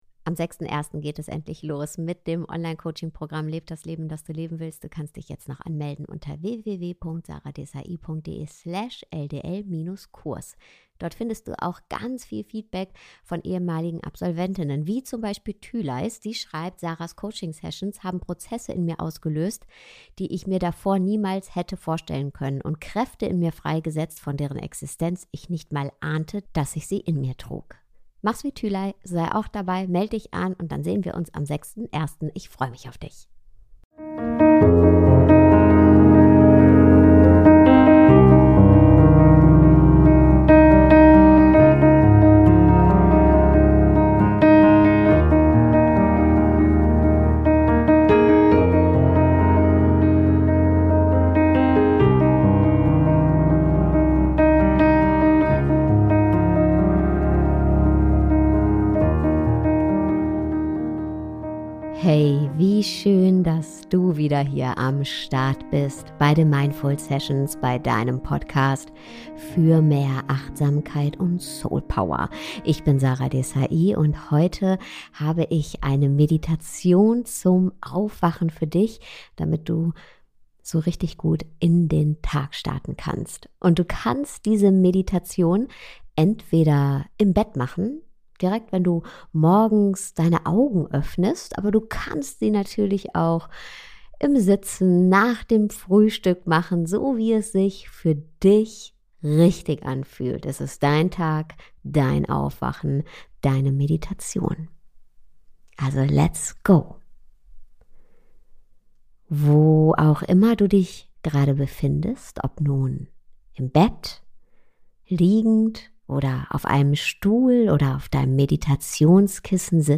Best Of: Selfcare Meditation